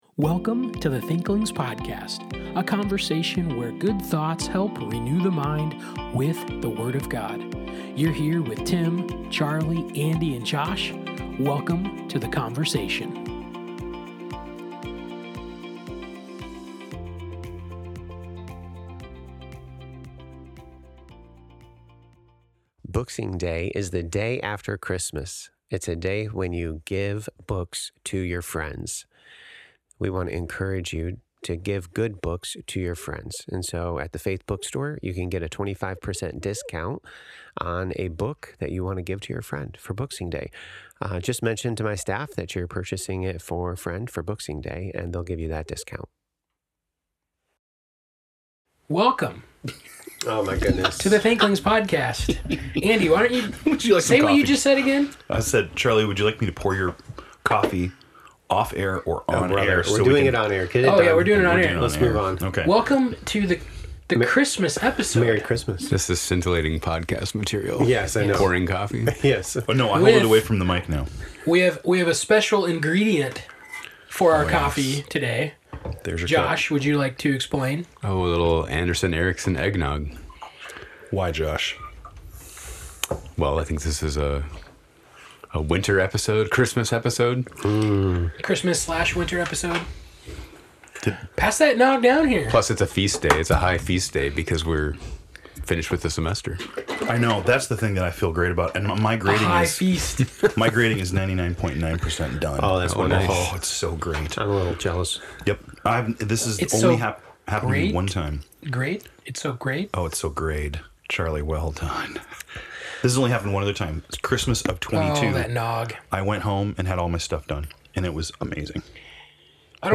In this episode, the Thinklings share the books they’re looking forward to reading now, over Christmas, and in the near future. It’s a forward-looking conversation about anticipation, curiosity, and the joy of planning good reading — whether for rest, growth, or simple delight.